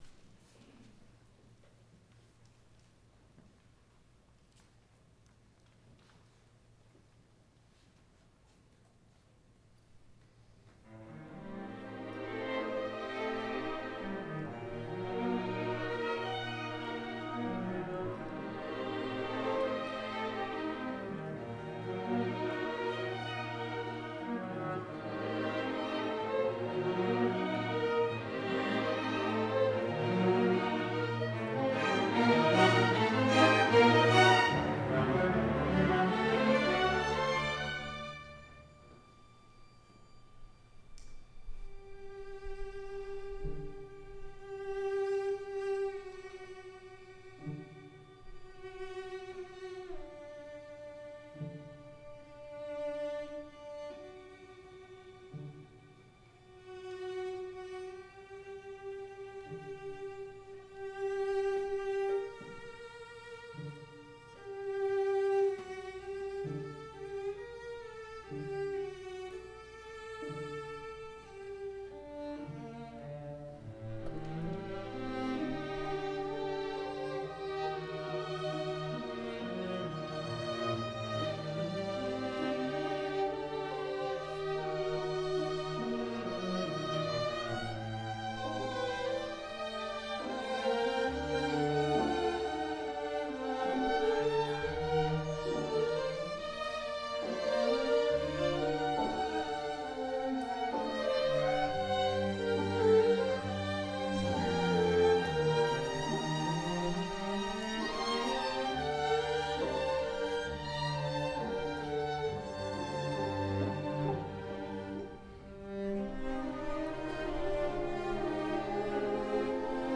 Instrumentation: String Orchestra
Theme: Judaica, Sacred Music